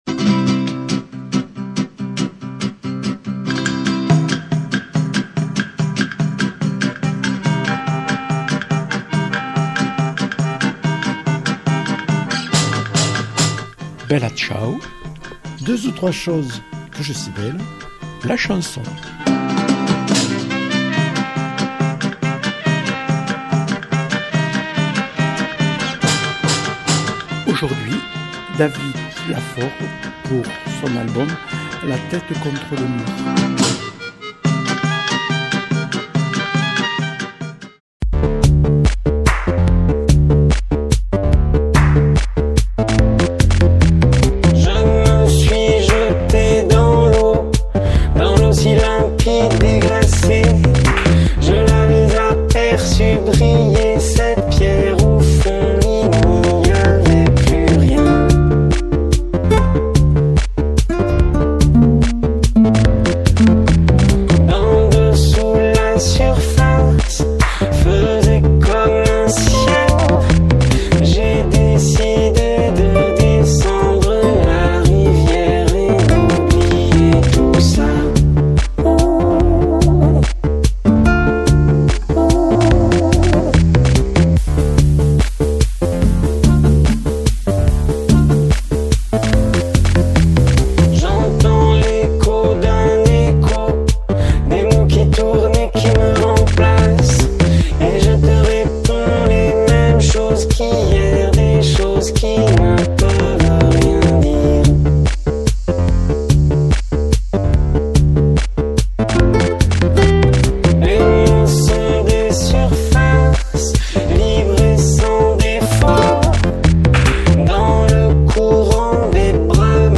Émissions
auteur-compositeur-interprète.